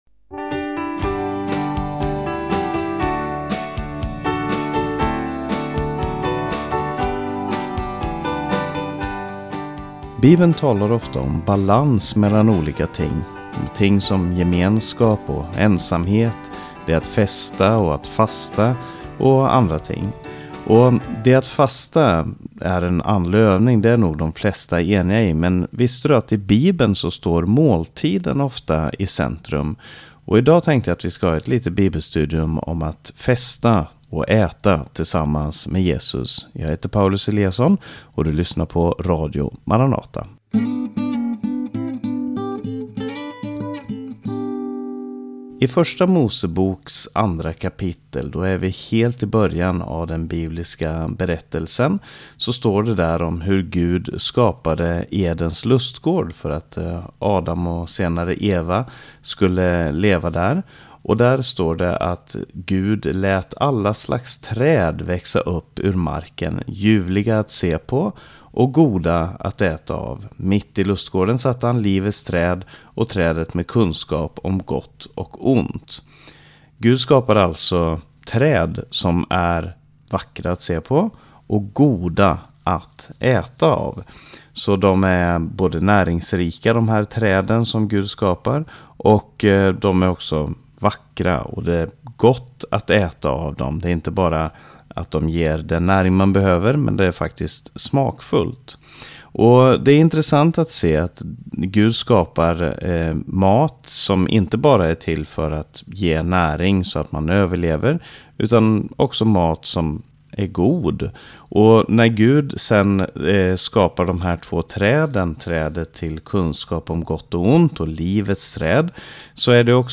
Ett bibelstudium över fester, måltider och bordsgemenskap i Bibeln.